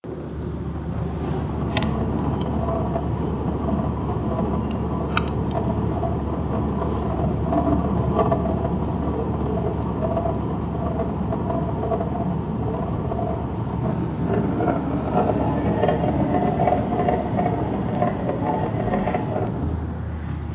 odglosyAuta.mp3